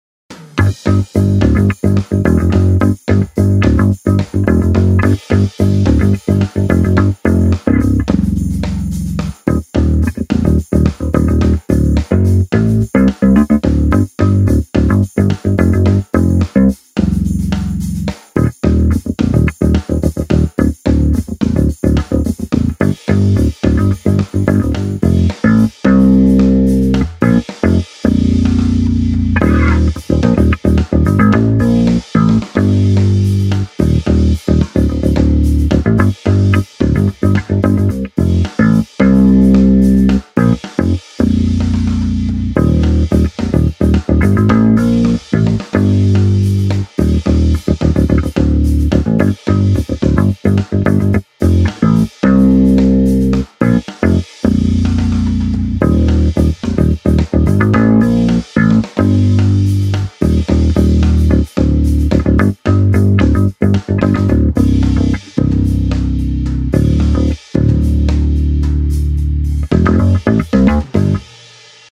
Real Vintage Bass